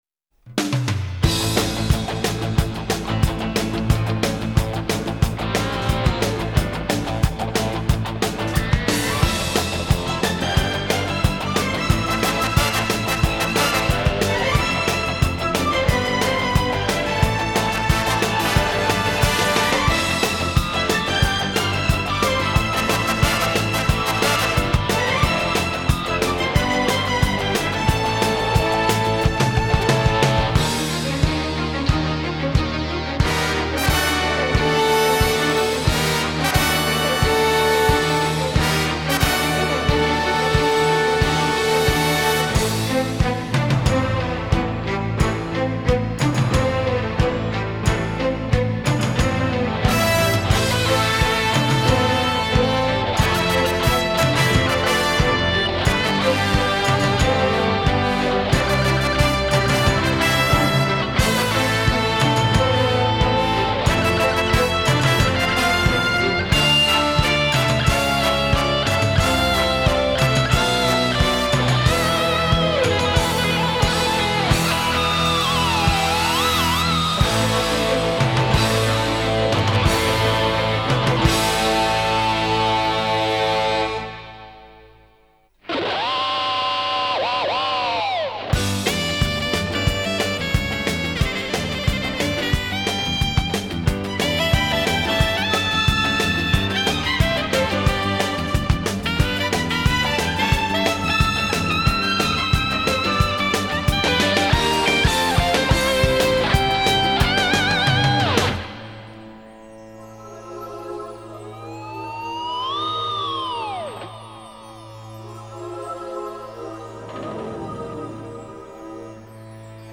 섹소폰 솔로 파트